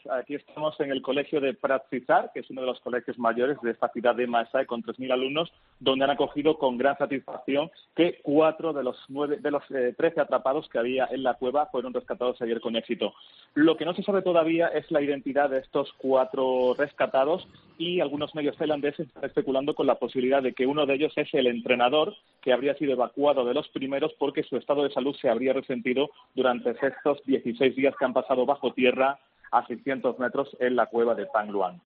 Se reanudan las labores de rescate de los niños Tailandeses. Crónica